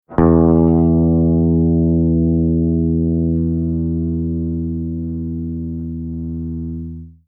Simply click the play button to get the sound of the note for each string (E, A, D, G, B and E).
Low E String
low-e-note.mp3